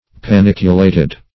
Search Result for " paniculated" : The Collaborative International Dictionary of English v.0.48: Paniculate \Pa*nic"u*late\, Paniculated \Pa*nic"u*la`ted\, a. [See Panicle .]